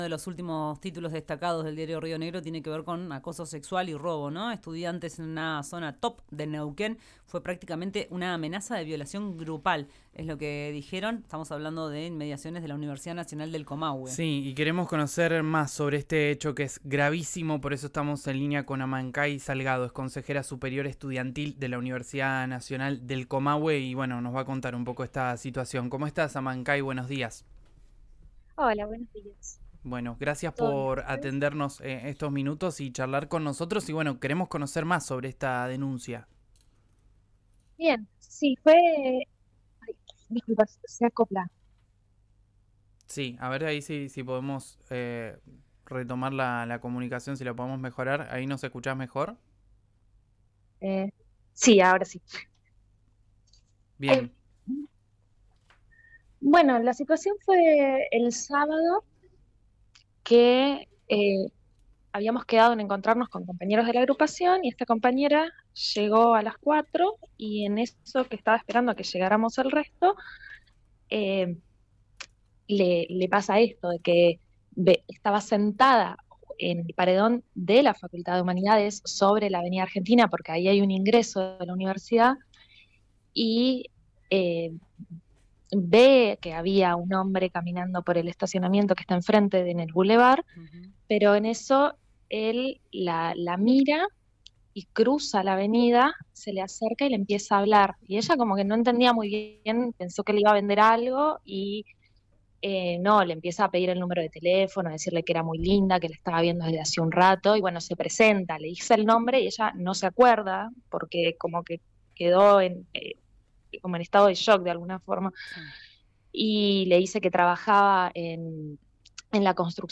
Habló con RIO NEGRO RADIO y denunció al acoso que sufrió su compañera cerca de la Facultad de Humanidades, sobre avenida Argentina y Albardón.